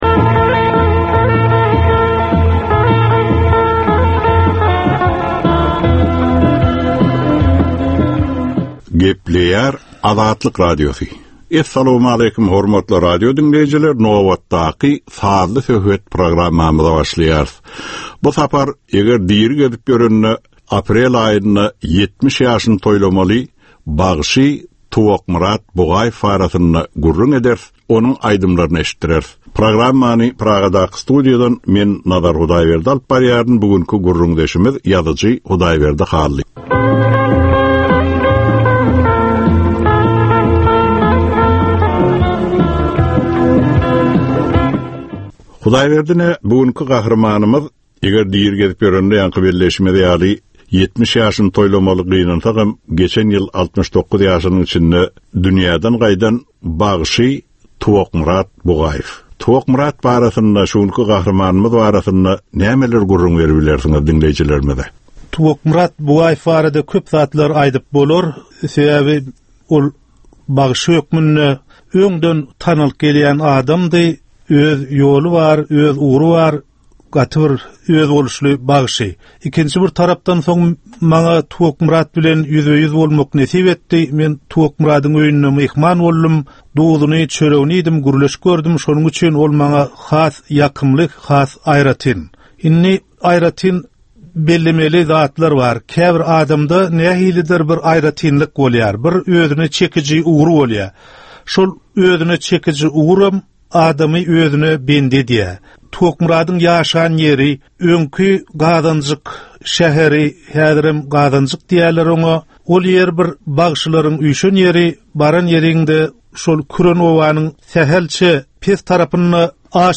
Türkmeniň käbir aktual meseleleri barada 30 minutlyk sazly-informasion programma.